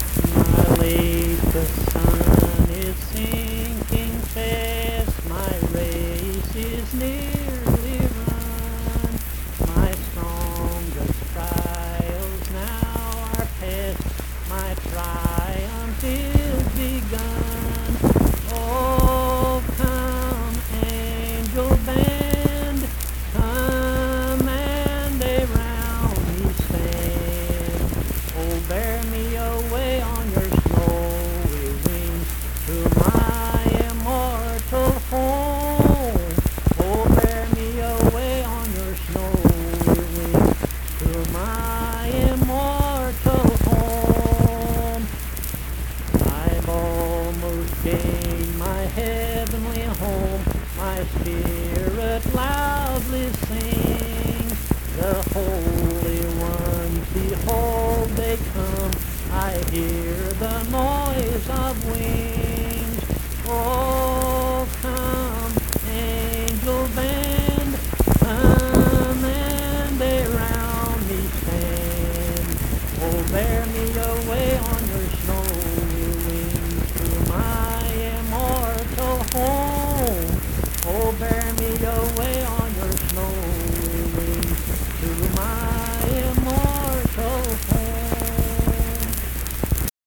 Accompanied (guitar) and unaccompanied vocal music
Verse-refrain 2(4)&R(4).
Performed in Mount Harmony, Marion County, WV.
Hymns and Spiritual Music
Voice (sung)